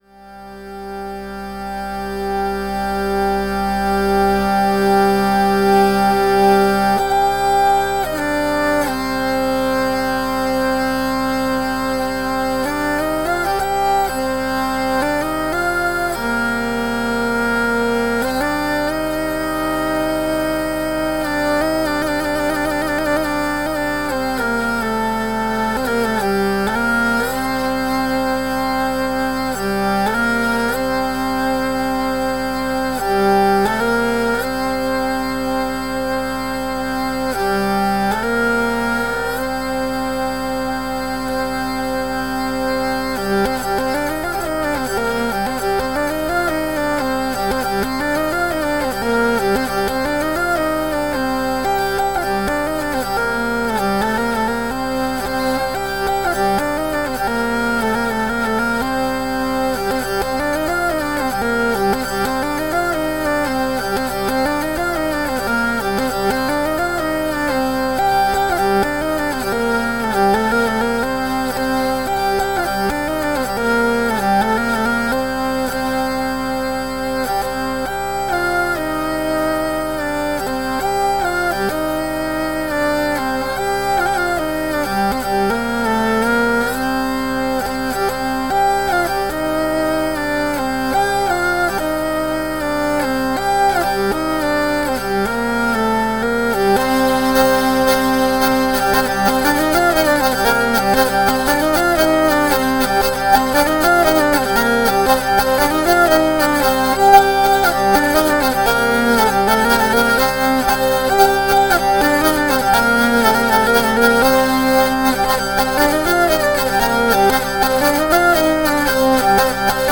Perhaps a little unusually, I play a fascinating instrument called a Hurdy Gurdy.
Here are some more recent efforts, recorded on my upgraded Dugue hurdy gurdy: same basic arrangement, just even more capos, and a jack plug enabling connection to amps and other electrical goods...
Here we have an old English dance, which dates from the early 16th century.
I love the fact that it has alternating tunes in different rhythms.
¬ it creates a drone sound